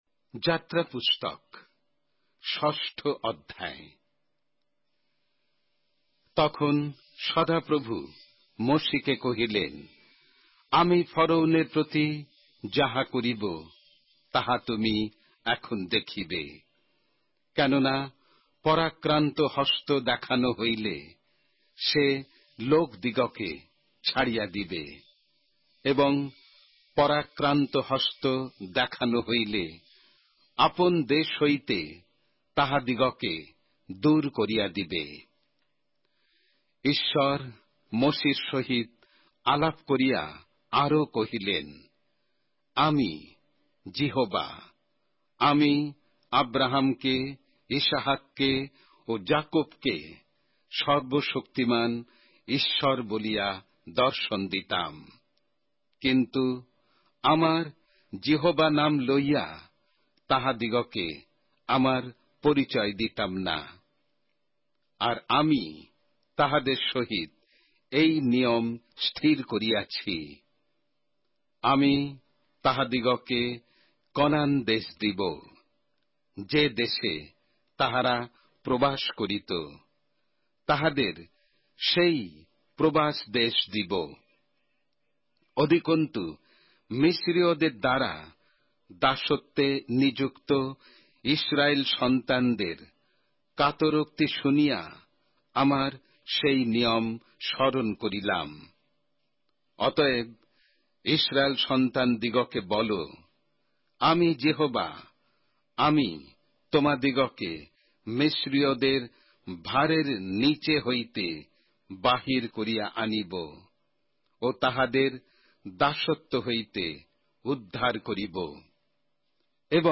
Exodus, chapter 6 of the Holy Bible in Bengali:অডিও আখ্যান সঙ্গে বাংলা পবিত্র বাইবেল অধ্যায়,